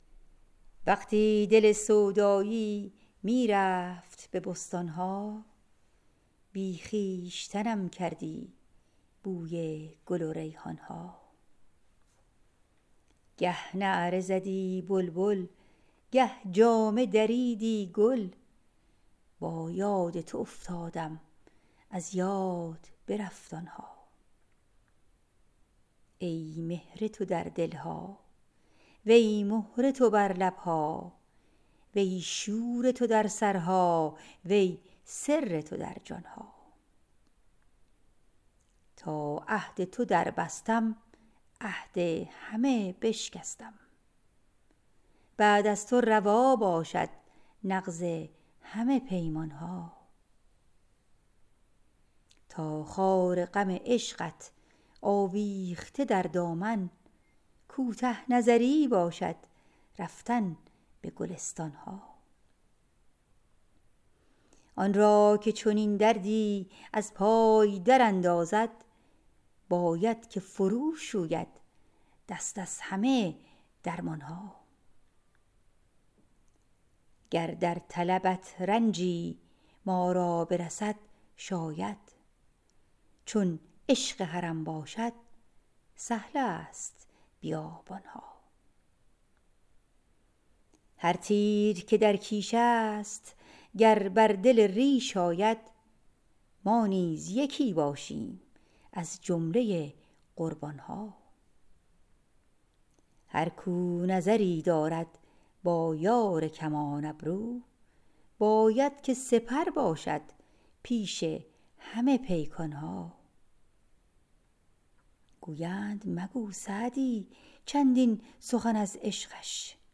Poem recited